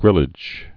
(grĭlĭj)